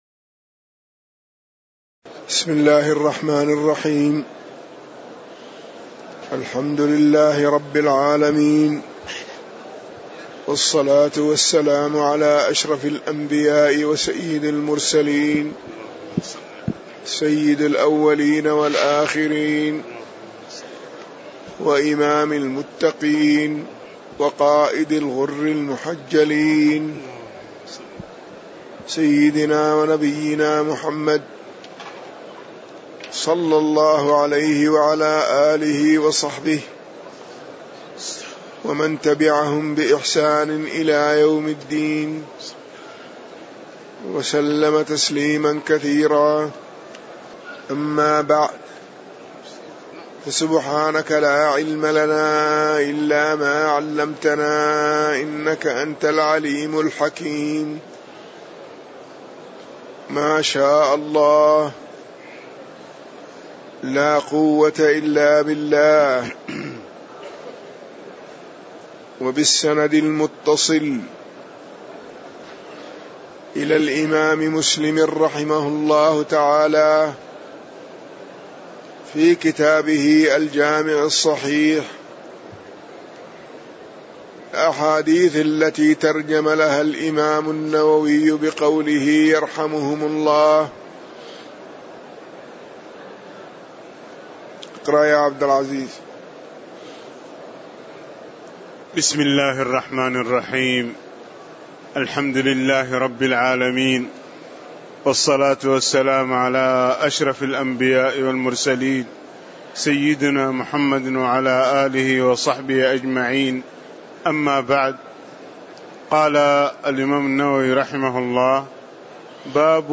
تاريخ النشر ١٩ رجب ١٤٣٨ هـ المكان: المسجد النبوي الشيخ